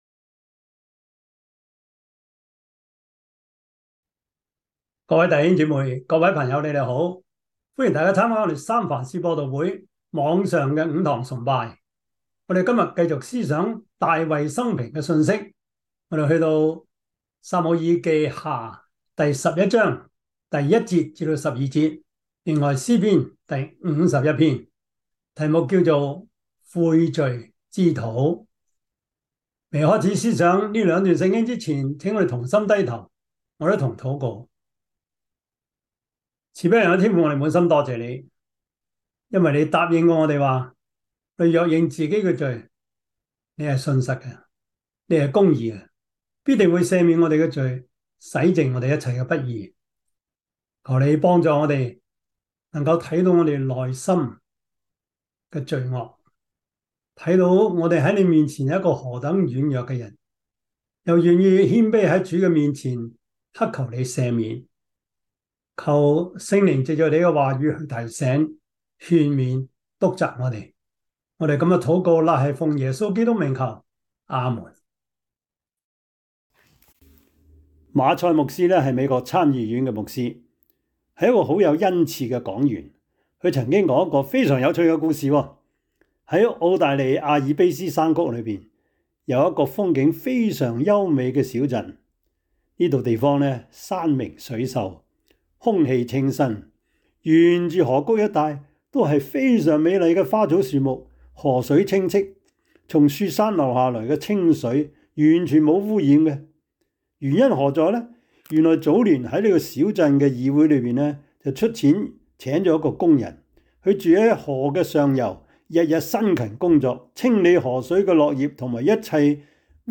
撒母耳記下 12:1-12 Service Type: 主日崇拜 撒母耳記下 12:1-12 Chinese Union Version
Topics: 主日證道 « 快樂人生小貼士 – 第八課 仰望等候耶和華 »